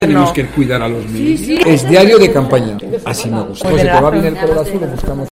Escobar ha entregado a los periodistas una libreta azul y aprovechado para sondear: ¿Te va bien el color?
Tras la rueda de prensa en la que ha presentado las propuestas del PP para apoyar a las familias, Conrado Escobar se ha acercado a los periodistas, bolsa en mano, y ha repartido unas libretas pequeñas bromeando con el color: azul, no podía ser de otra manera.